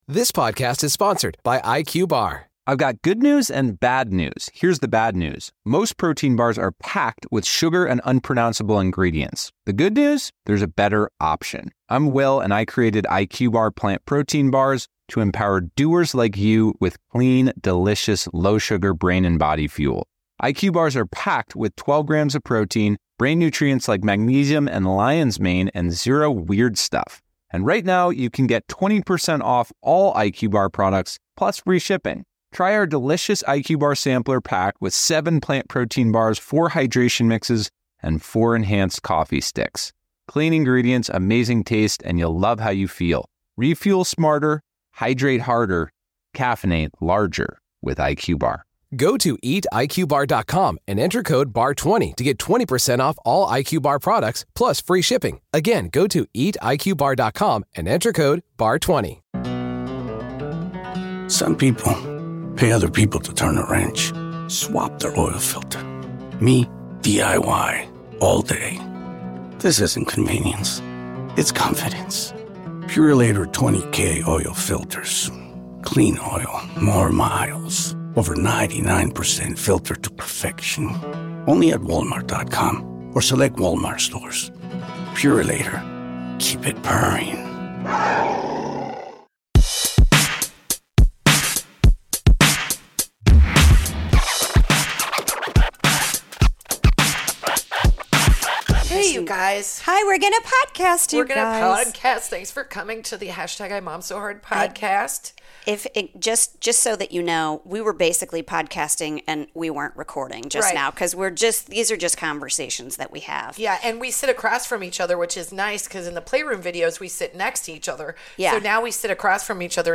ABOUT US: This hilarious comedy podcast about motherhood is for moms by moms talking all about being a mom.
Female comedy duo